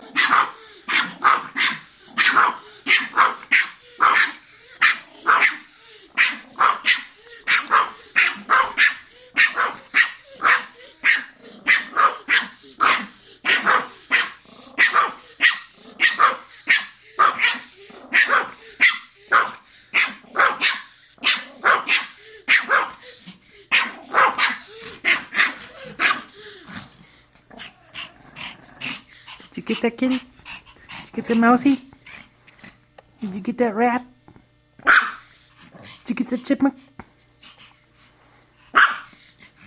Звуки лая шпица
два шпица облаивают друг друга